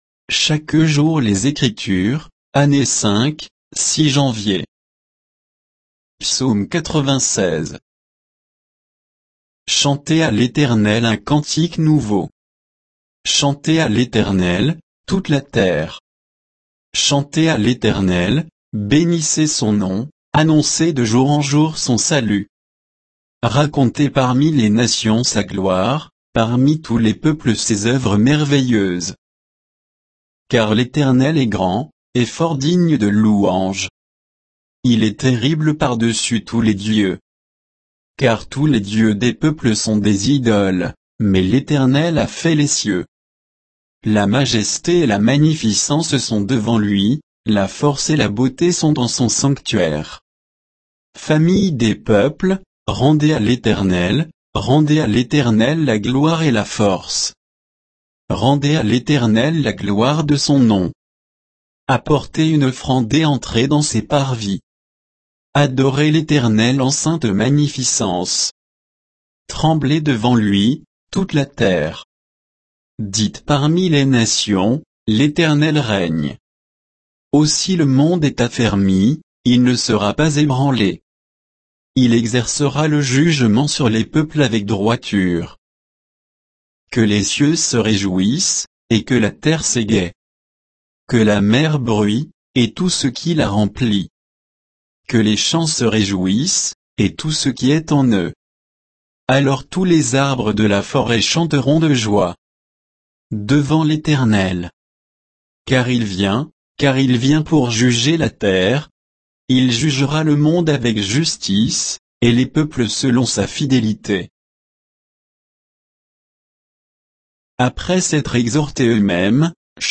Méditation quoditienne de Chaque jour les Écritures sur Psaume 96